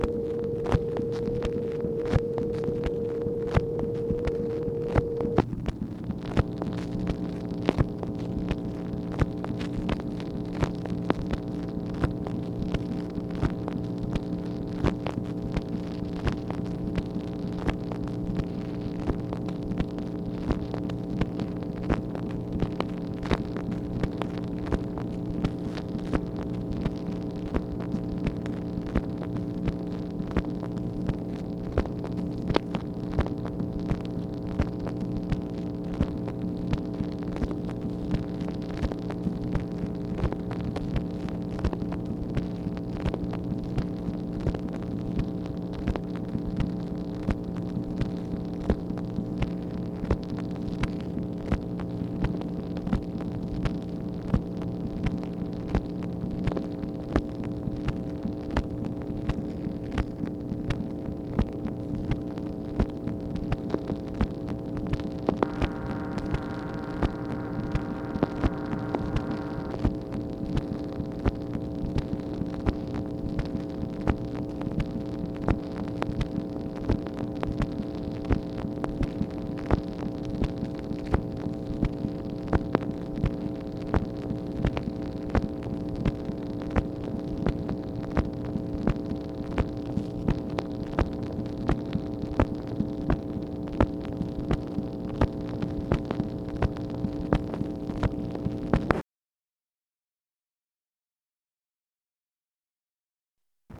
MACHINE NOISE, May 27, 1965
Secret White House Tapes | Lyndon B. Johnson Presidency